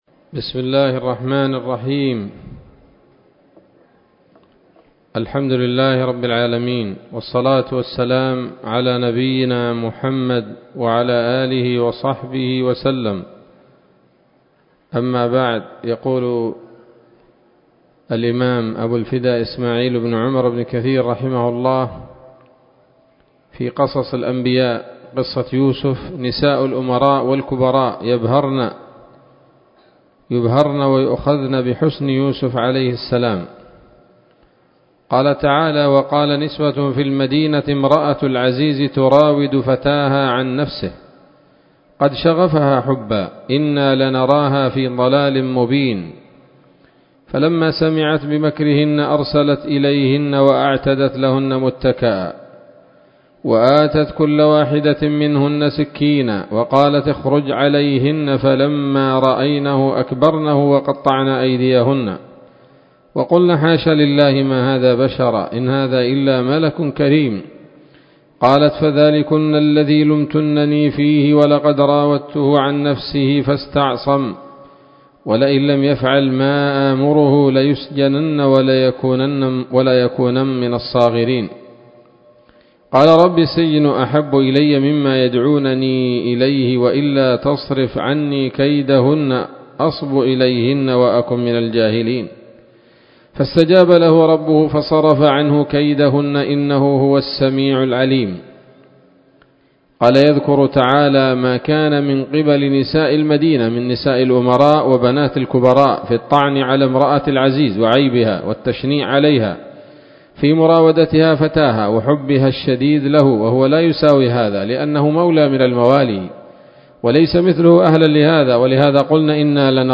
الدرس الثامن والستون من قصص الأنبياء لابن كثير رحمه الله تعالى